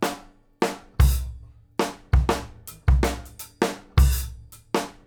GROOVE 8 07L.wav